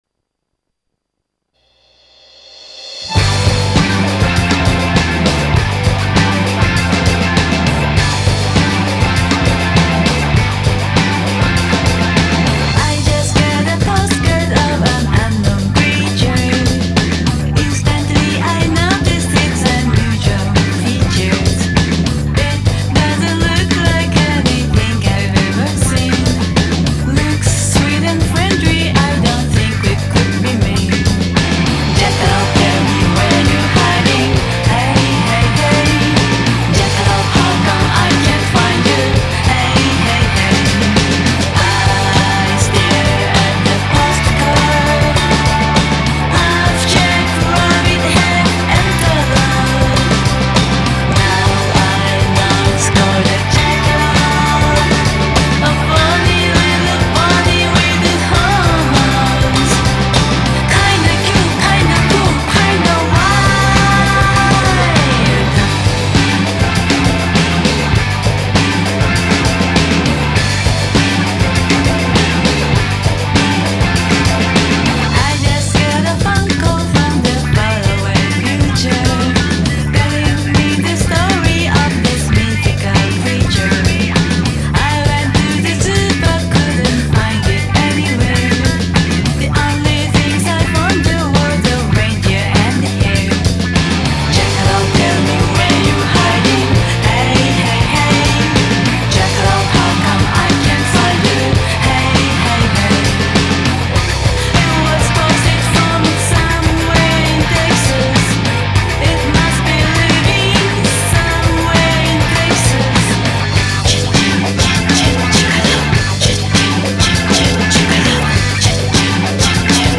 japanese girlband